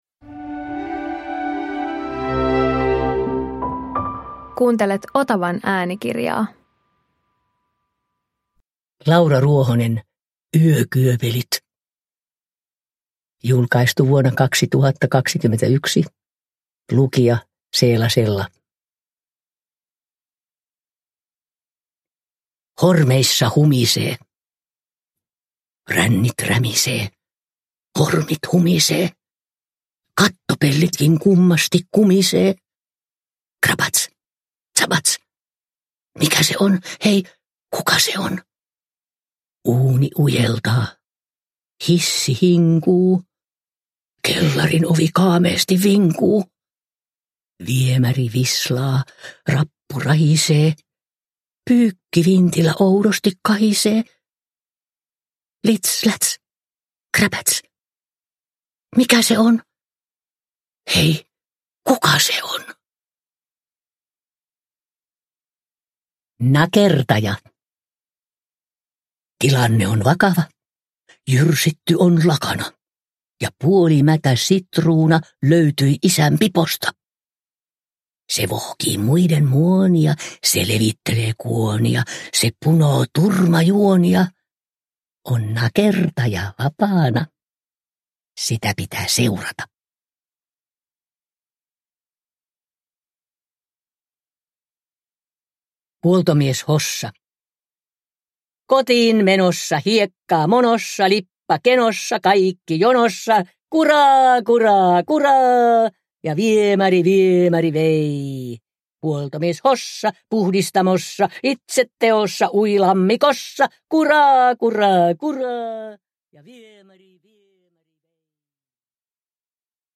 Yökyöpelit – Ljudbok
Iki-ihanat, niin lasten kuin kriitikoidenkin ylistämät runot julkaistaan Seela Sellan tulkitsemana äänikirjana.
Uppläsare: Seela Sella